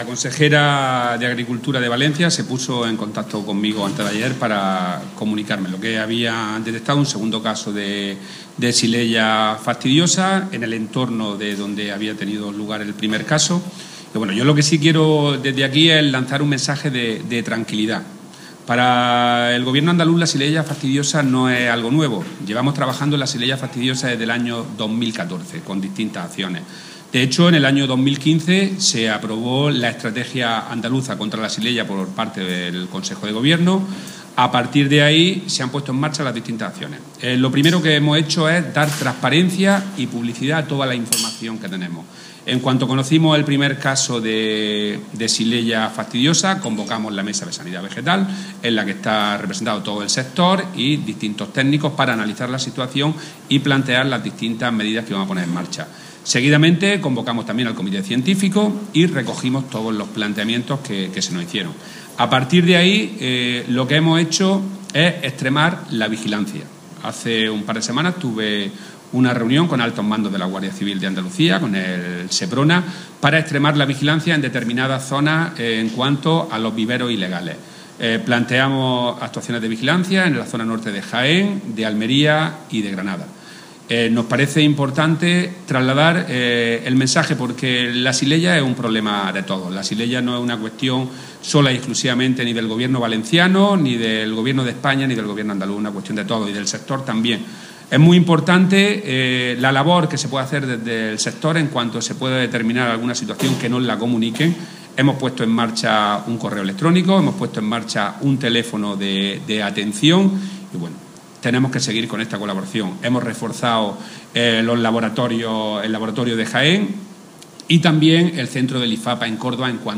Declaraciones de Rodrigo Sánchez sobre Xylella fastidiosa